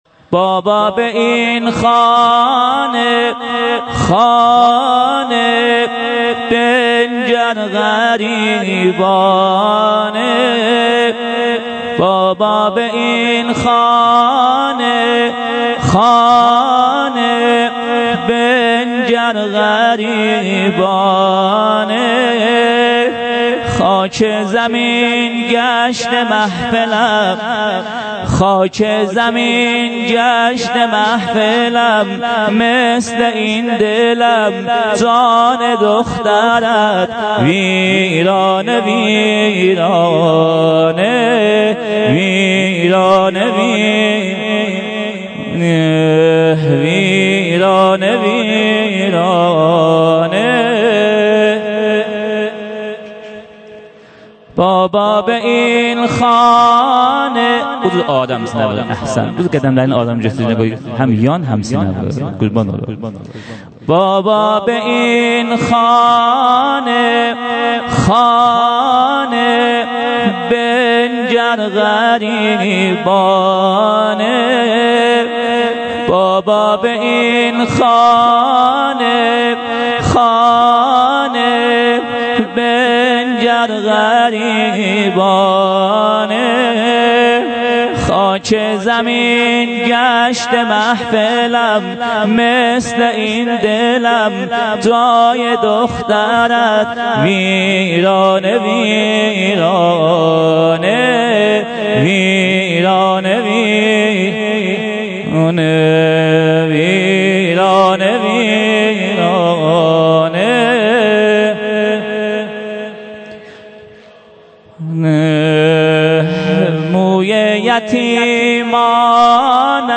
هیأت محبان اهل بیت علیهم السلام چایپاره
محرم 97 - شب سوم - بخش اول سینه زنی